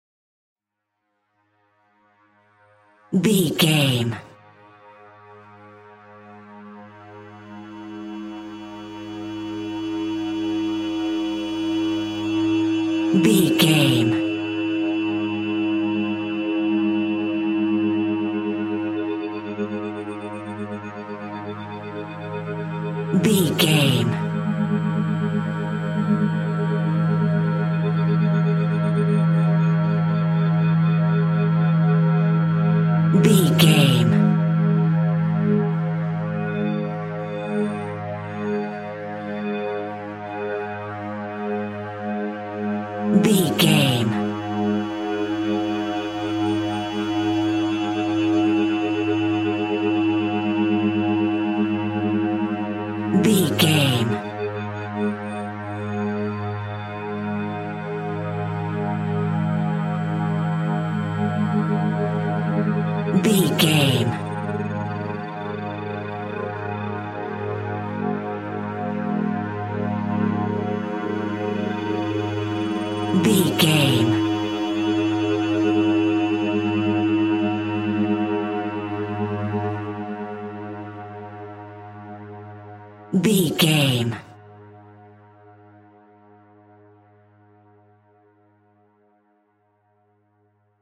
Aeolian/Minor
G#
Slow
scary
ominous
dark
suspense
haunting
eerie
synthesiser
horror
Synth Pads
atmospheres